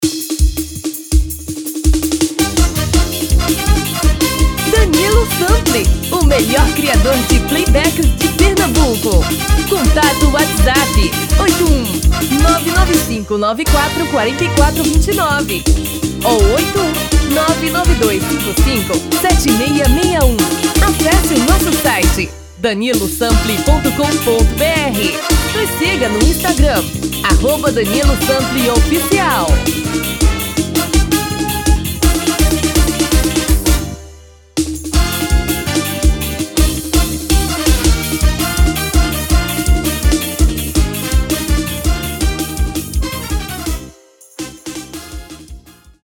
TOM FEMININO